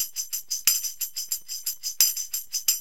TAMB LP 90.wav